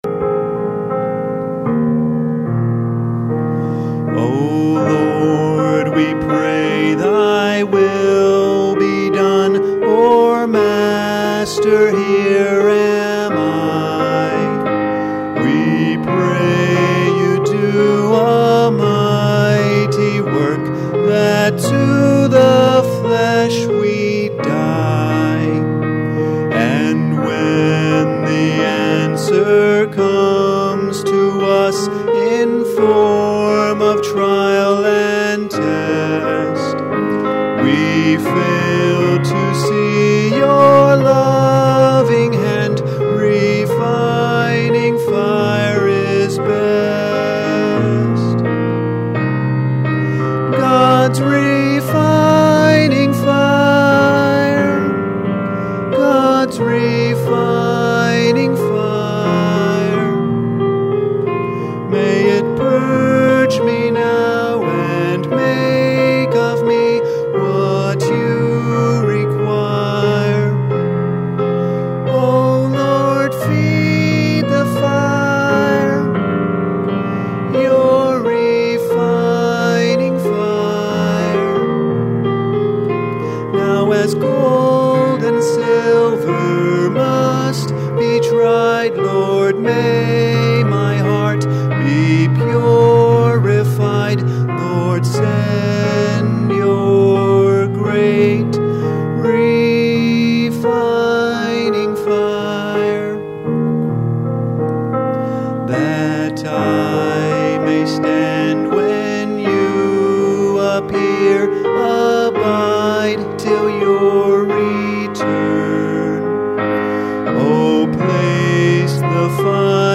Our Seven Responsibilities towards Our Church Leaders AM Service